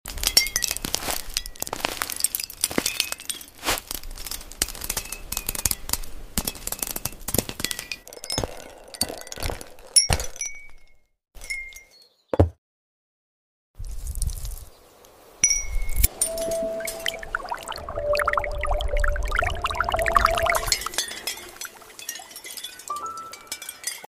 ✨ ASMR magic begins when sound effects free download
✨ ASMR magic begins when silence shimmers.